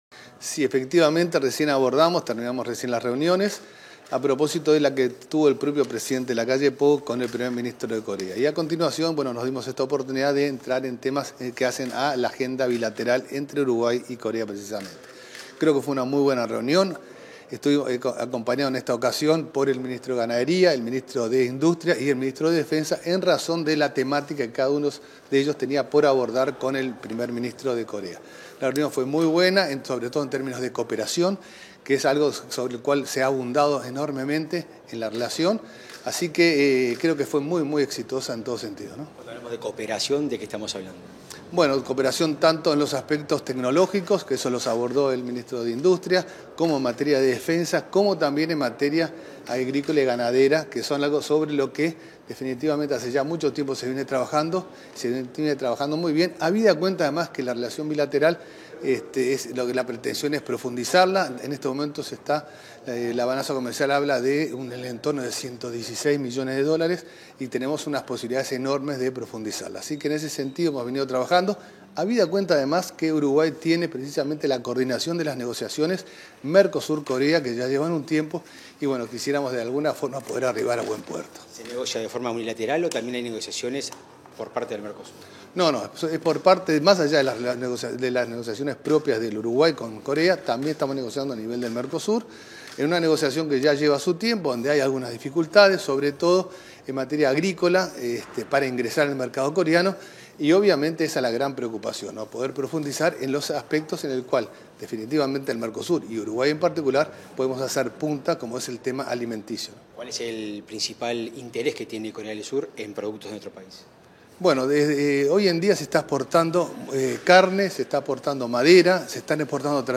Entrevista al ministro de Relaciones Exteriores, Francisco Bustillo